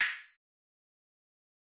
kits/TM88/Closed Hats/PBS - (HAT) 8888.wav at ts
PBS - (HAT) 8888.wav